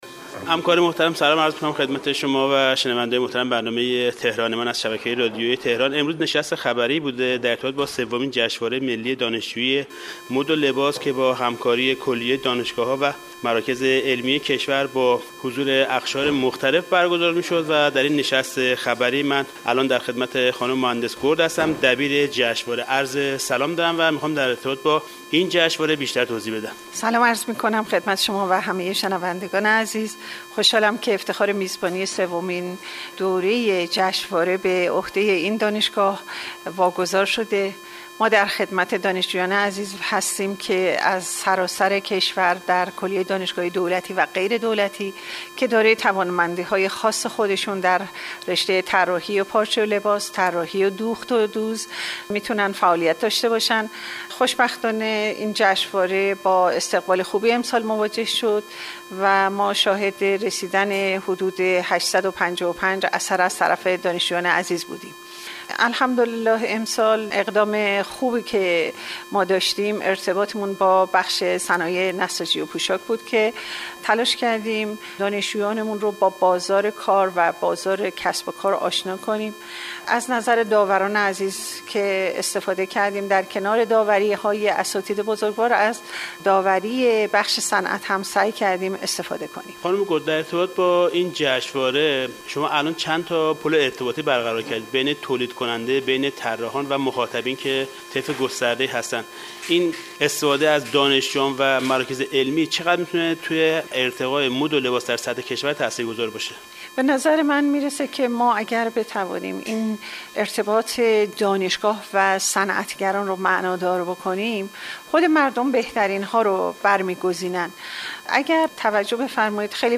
مصاحبه رادیو